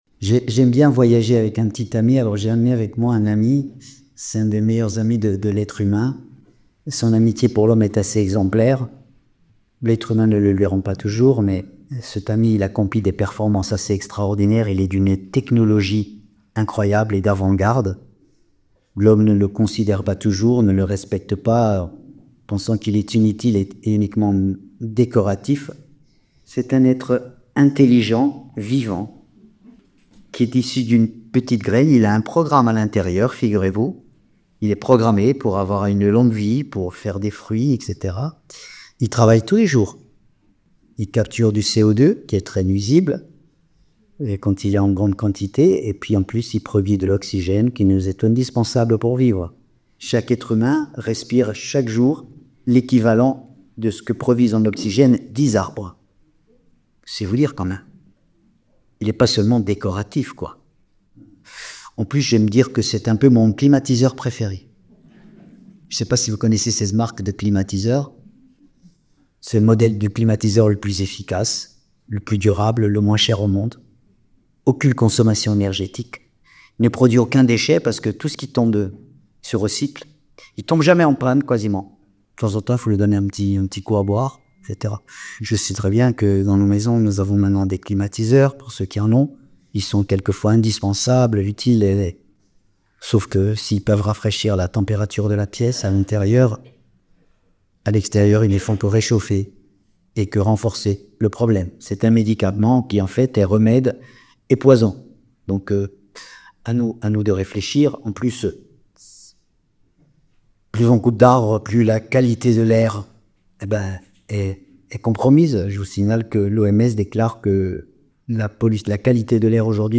Conférence de la semaine du 08 mai